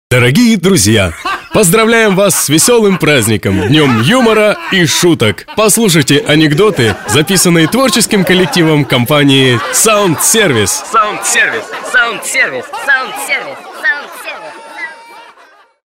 Сотрудники студии звукозаписи «СаундСервис» в честь праздника создали и записали несколько приколов и шуток.